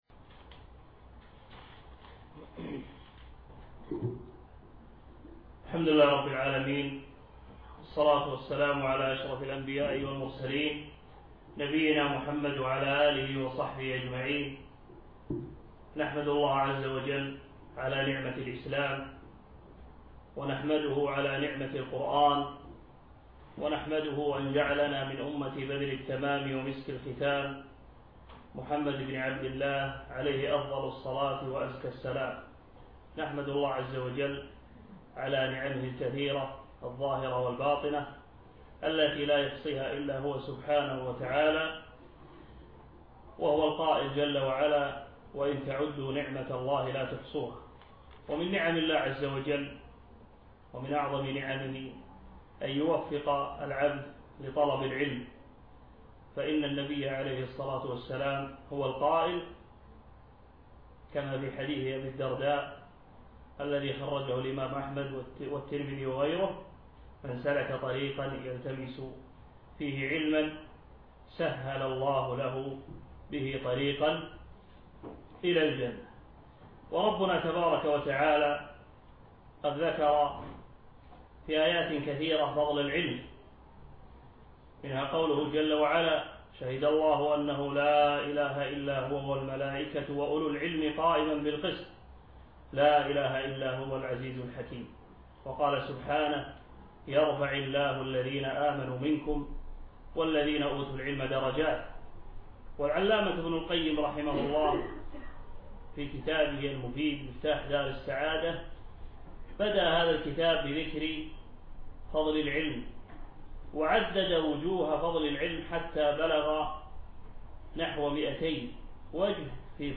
أقيمت هذه الدورة بمركز القصر نساء مسائي
الدرس الأول